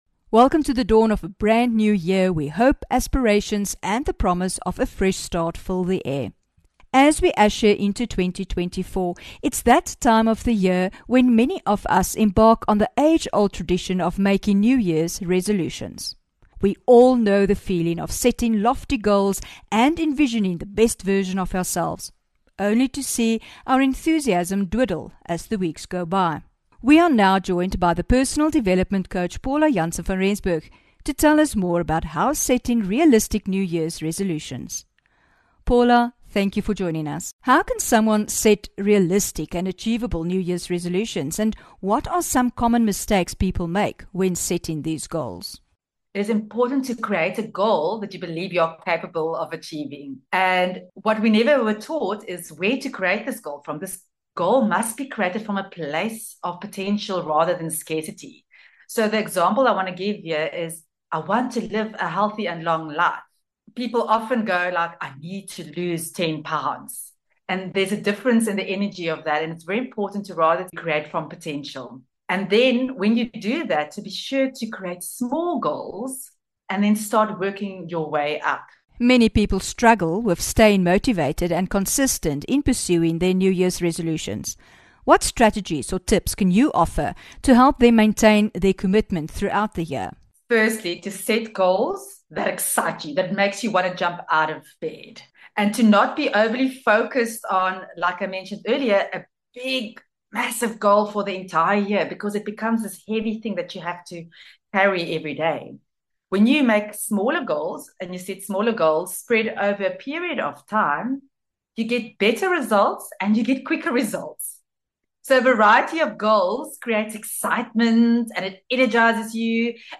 30 Jan INTERVIEW